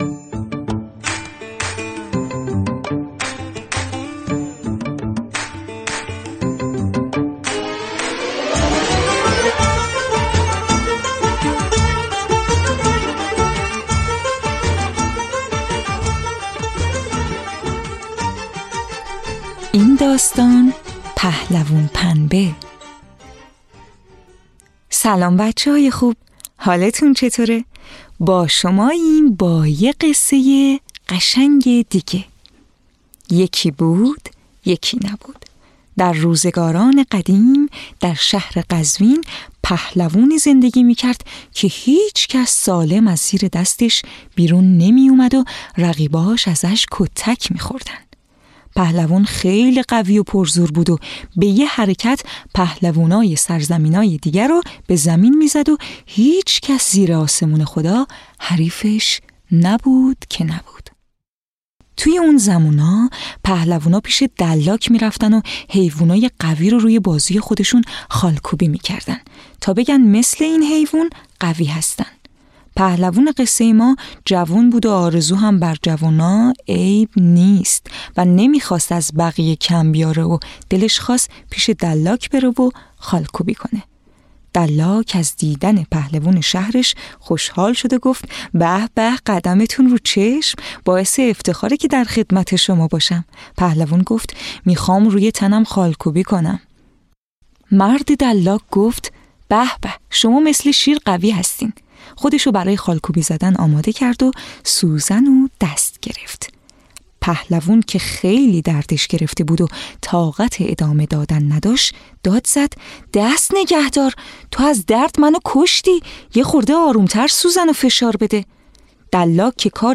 قصه های کودکانه صوتی - این داستان: پهلوون پنبه
«پهلوون پنبه» با لحنی طنزآمیز و ماجرایی بامزه، کودکان را با معنای واقعی شجاعت آشنا می کند.
تهیه شده در استودیو نت به نت